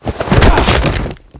tackle1.ogg